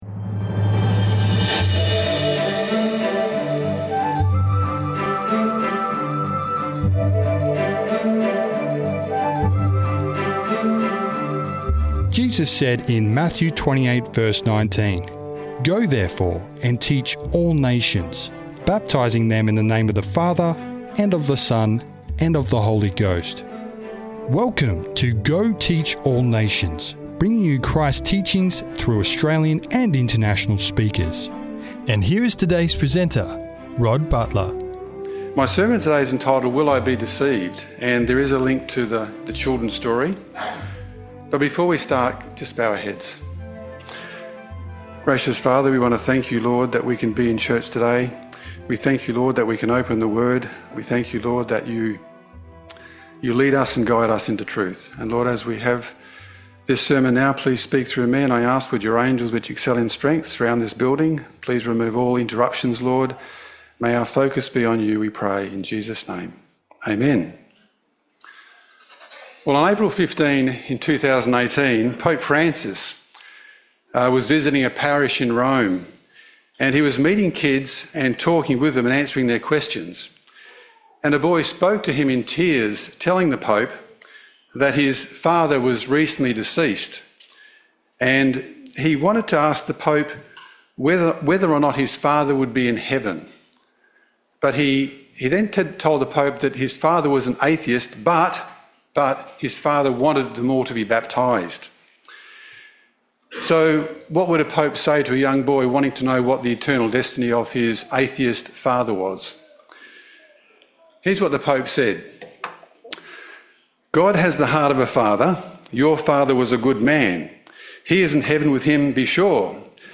This message was made available by the Dora Creek Seventh-day Adventist church.